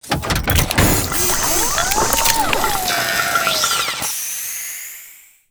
warpfuel.wav